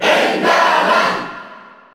Category:Crowd cheers (SSBU) You cannot overwrite this file.
Enderman_Cheer_Spanish_PAL_SSBU.ogg